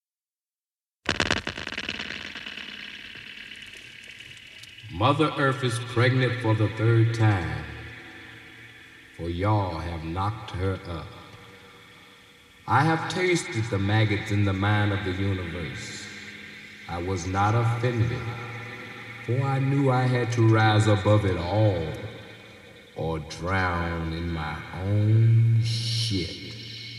smoking rant